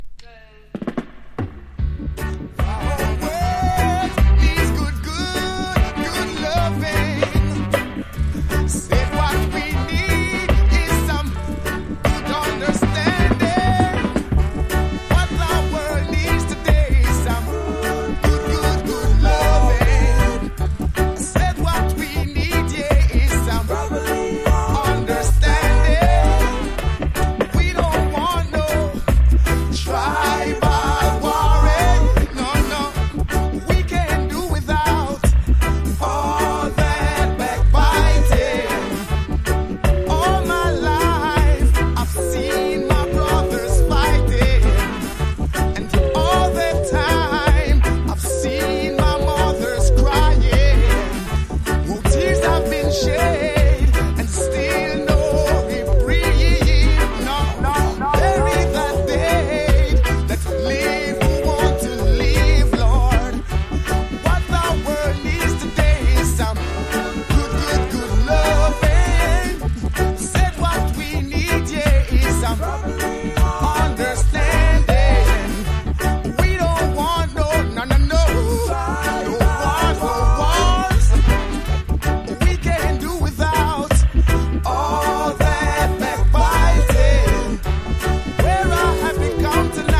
• REGGAE-SKA
所によりノイズありますが、リスニング用としては問題く、中古盤として標準的なコンディション。